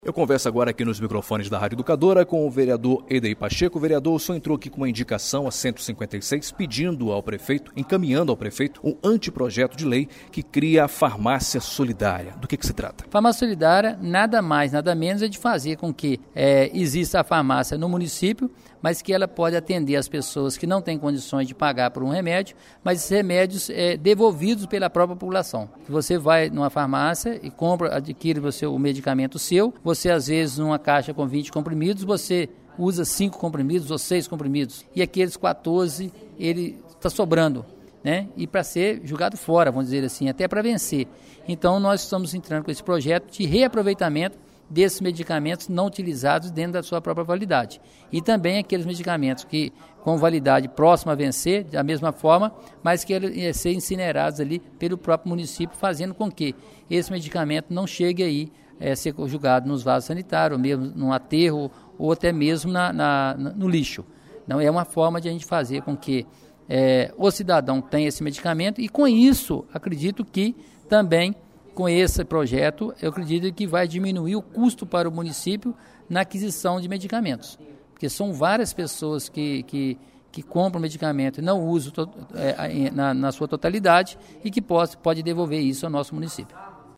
VEREADOR EDEIR PACHECO FALA DO PROJETO FARMÁCIA SOLIDÁRIA